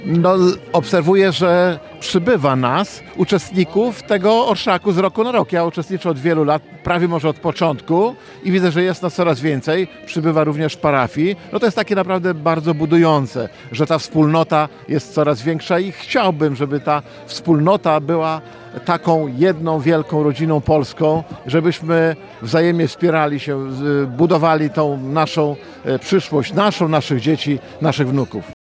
Starosta łomżyński Lech Szabłowski zwracał uwagę, że Orszak z roku na rok jest liczniejszy.